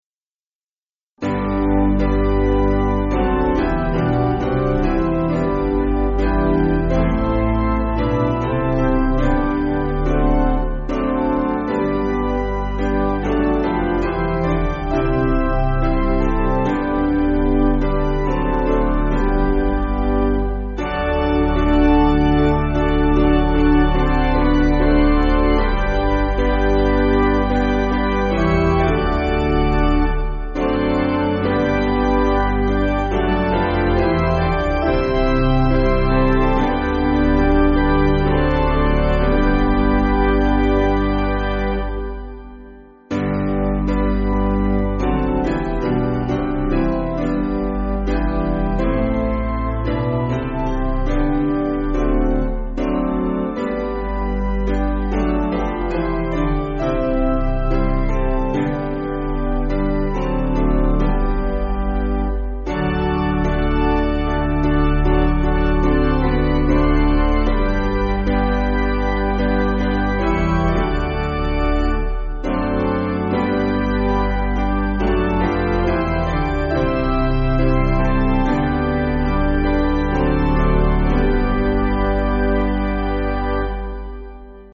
Basic Piano & Organ